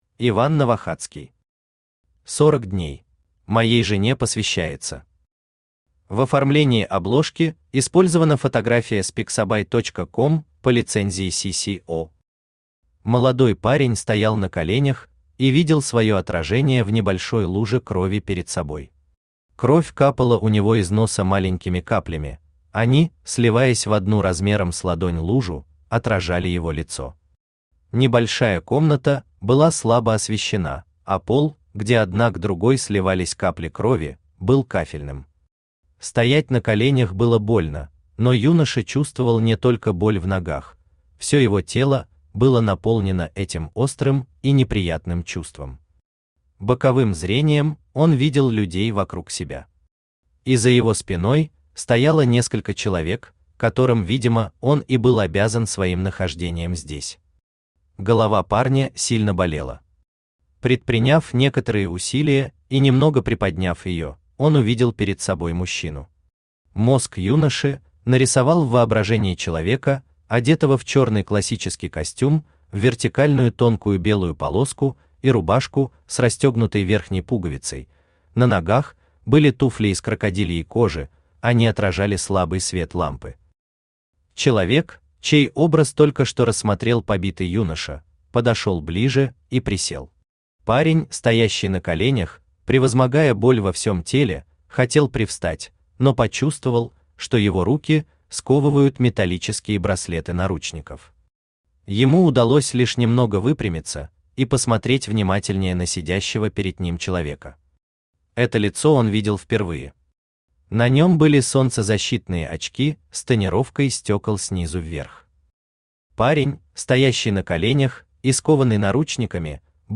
Аудиокнига 40 дней | Библиотека аудиокниг
Aудиокнига 40 дней Автор Иван Викторович Новохацкий Читает аудиокнигу Авточтец ЛитРес.